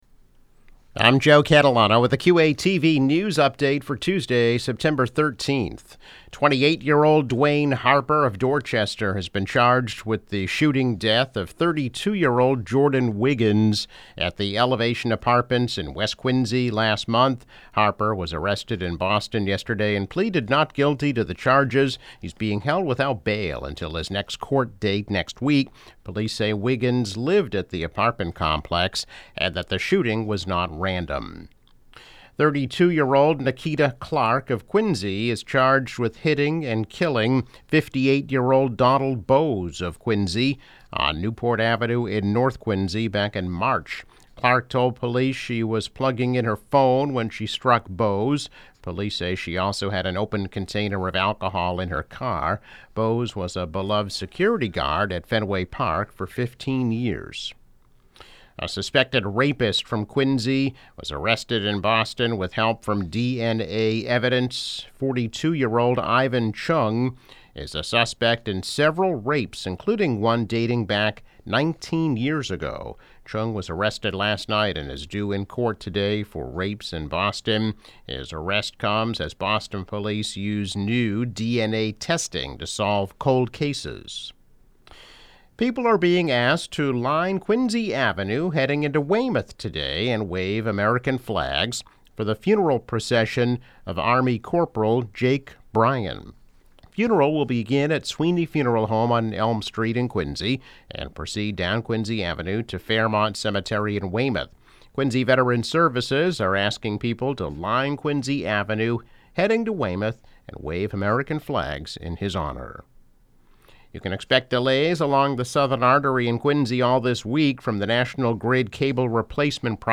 News Update - September 13, 2022